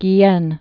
(gē-ĕn)